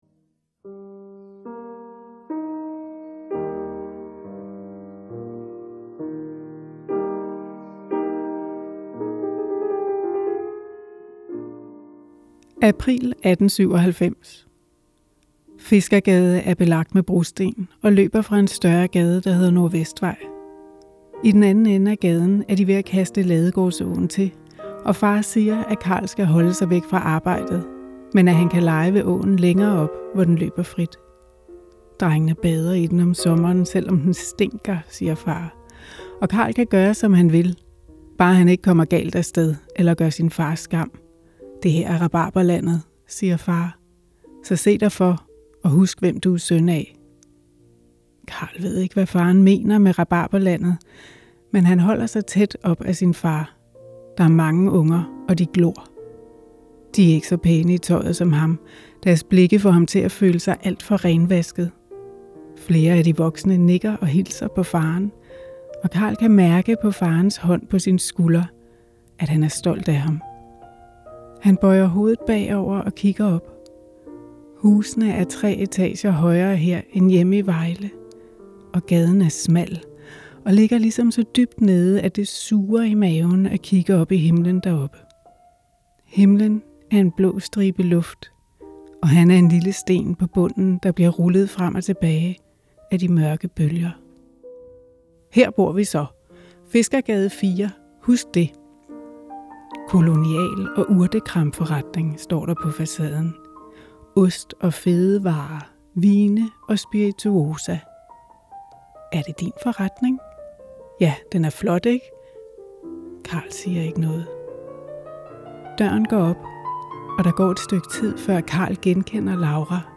læse højt om Carl Fischers møde med Fiskergade.